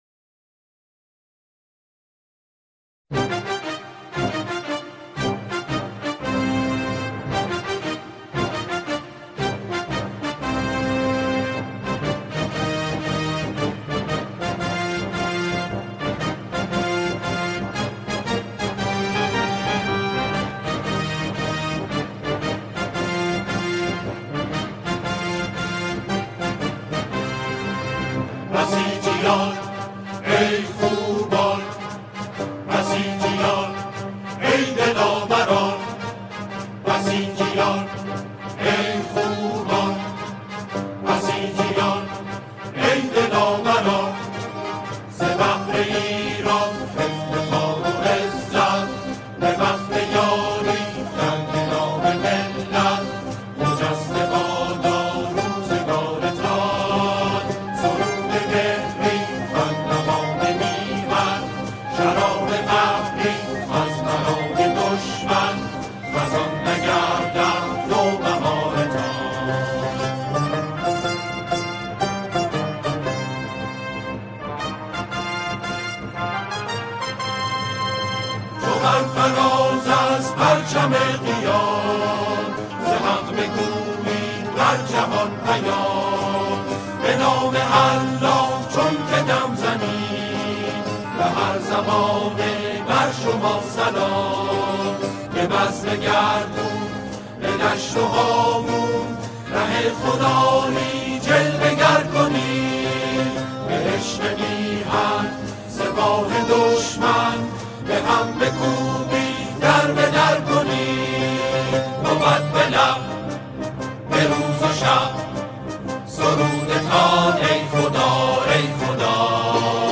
سرودهای بسیج